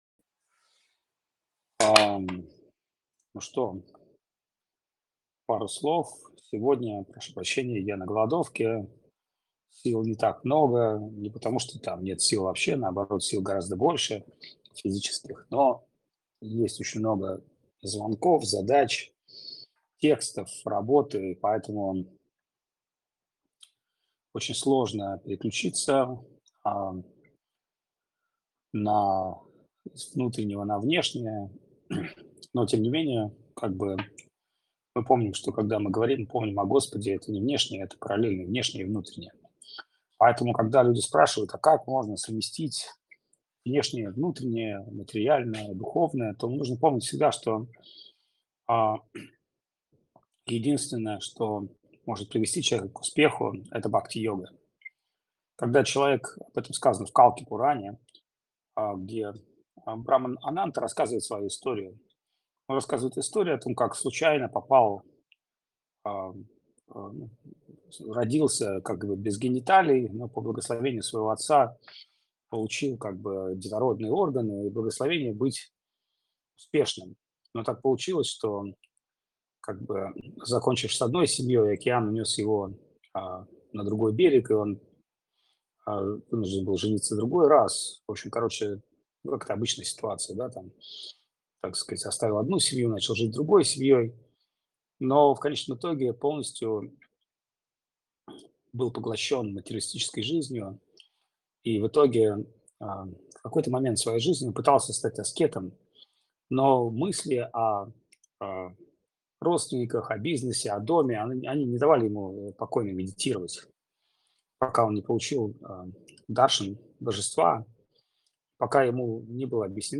Чиангмай, Таиланд
Лекции полностью